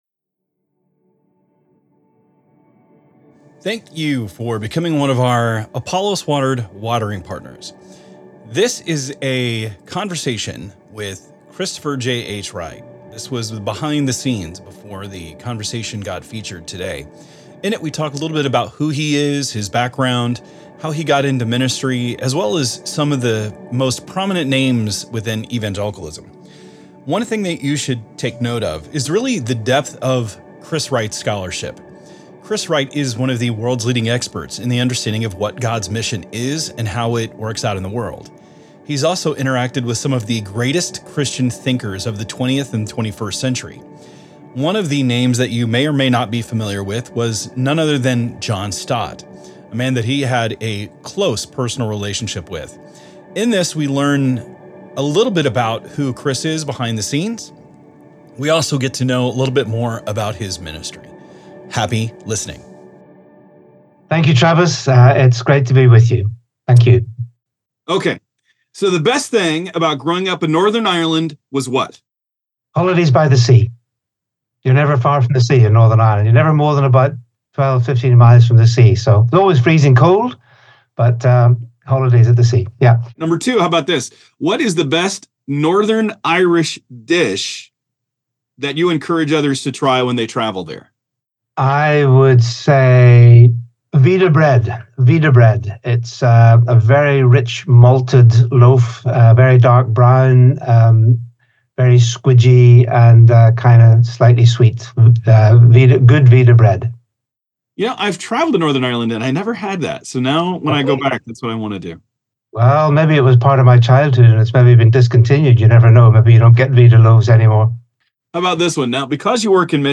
In a compelling conversation